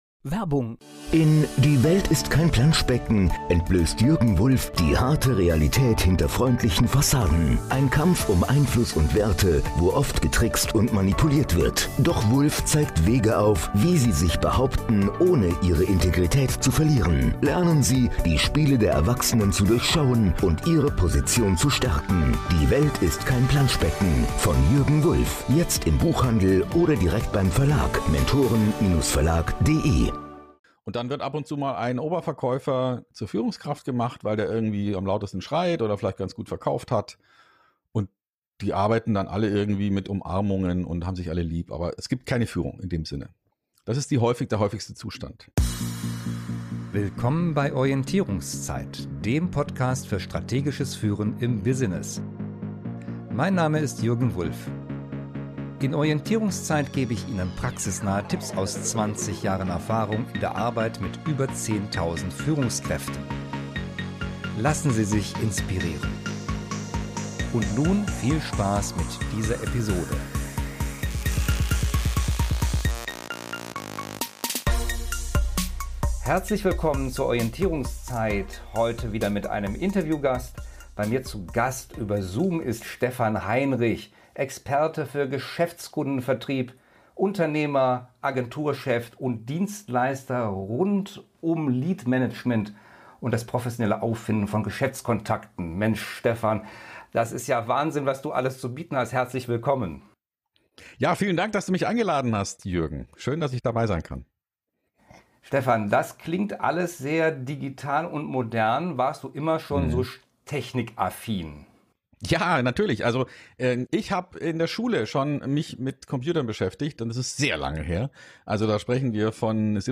im Interview.